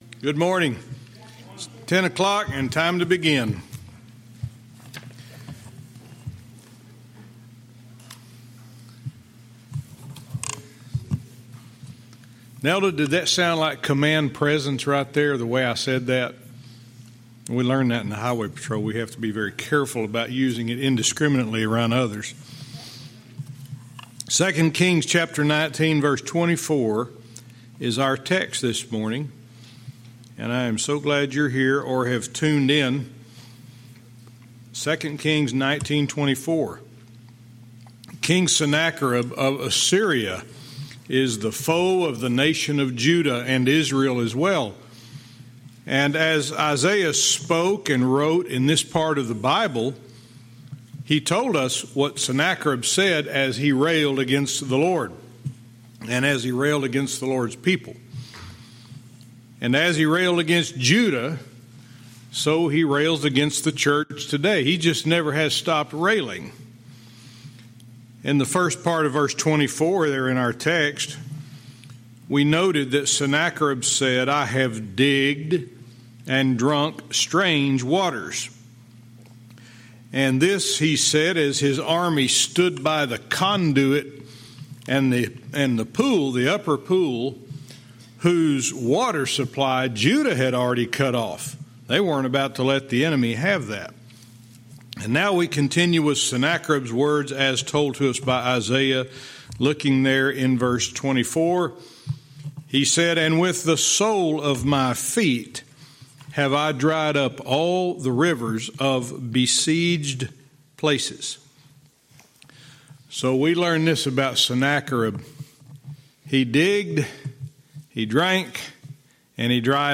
Verse by verse teaching - 2 Kings 19:24-26